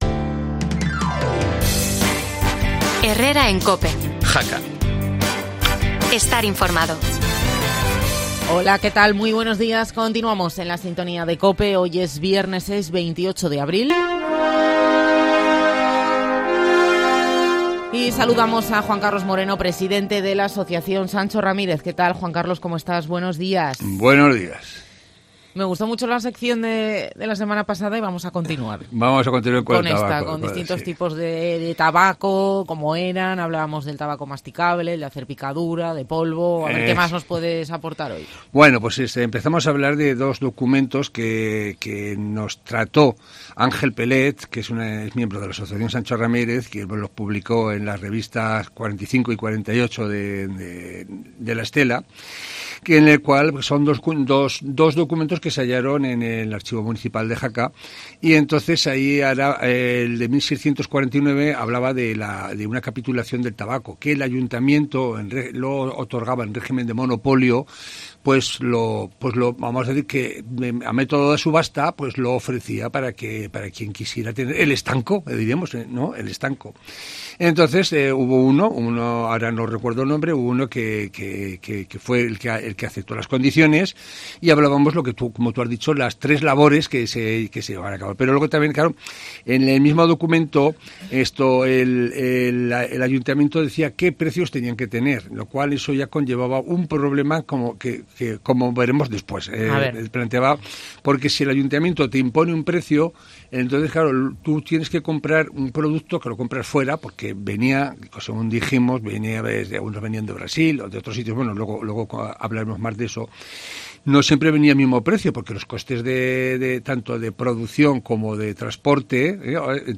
En 1.882, la calidad del tabaco en la localidad era tan mala que algunos fumadores iban a buscarlo a pueblos limítrofes de la provincia de Zaragoza. ¿Quieres saber más? No te pierdas la entrevista en COPE.